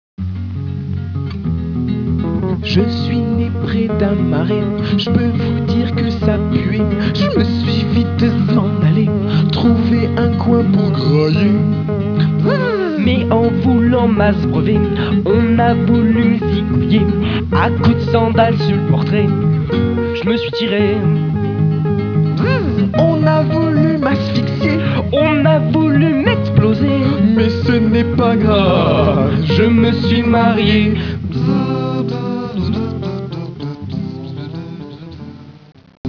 Extrait de la bande sonore (338 Ko)